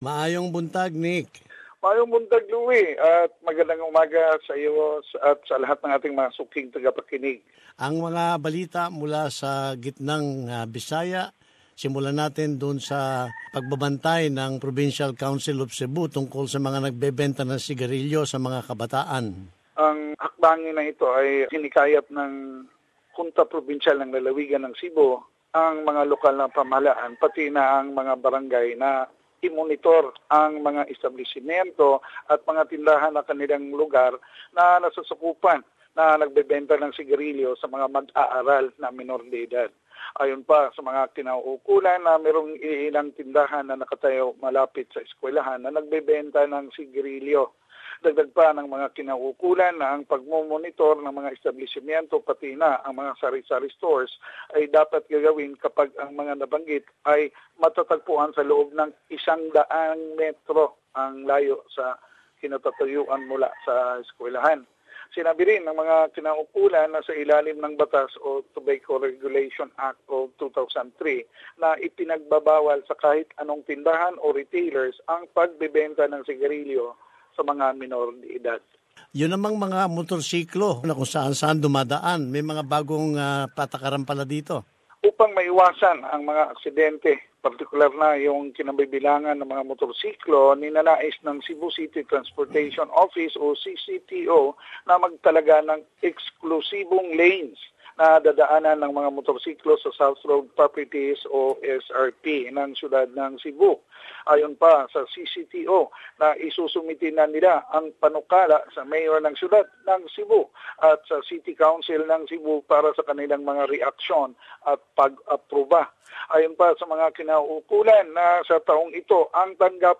The Provincial Goverment of Cebu has issued a new directive prohibiting retail outlest from selling cigarettes to minors. Part of the weekly news report